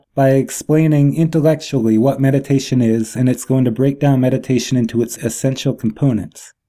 This is a segment around :38, before and after a fluff correction.